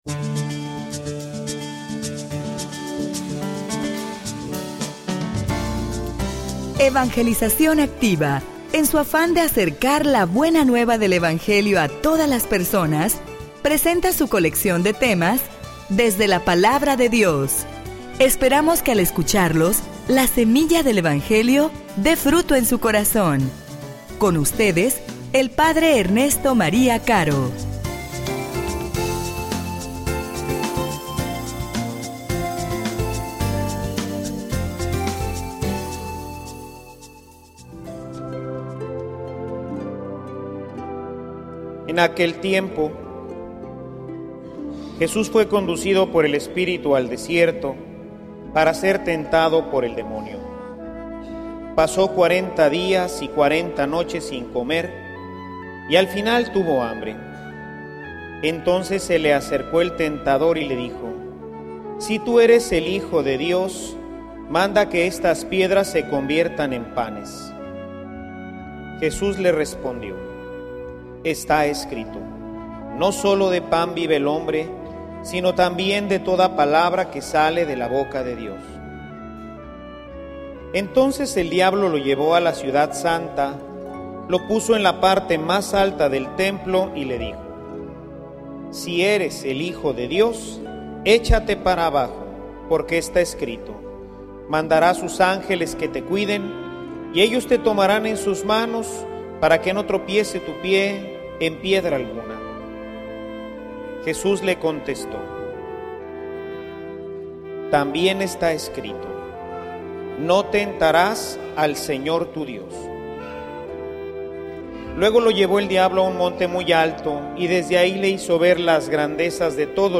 homilia_Desobedecer_a_Dios_tiene_consecuencias.mp3